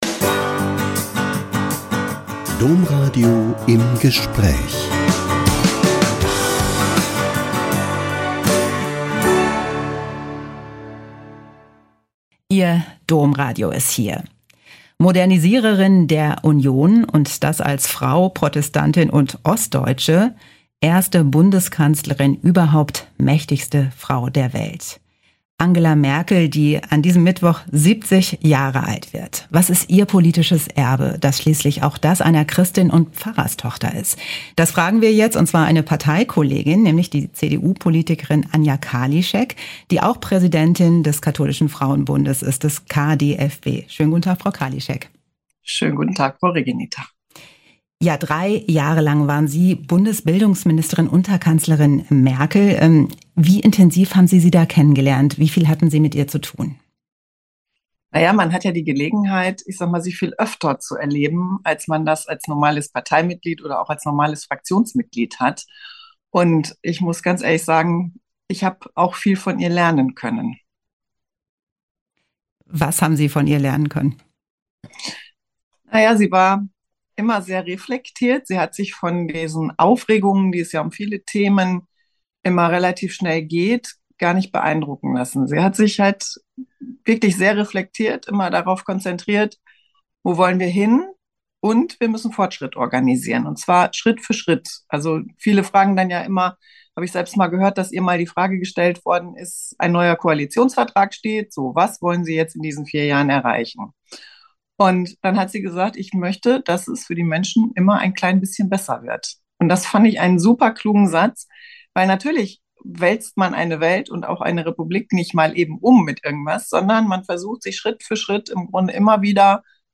Ex-Ministerin würdigt Leistungen Merkels zu ihrem 70. Geburtstag - Ein Interview mit Anja Karliczek (CDU-Bundestagsabgeordnete, frühere Bundesforschungs- und bildungsministerin, Präsidentin des Katholischen Deutschen Frauenbunds KDFB) ~ Im Gespräch Podcast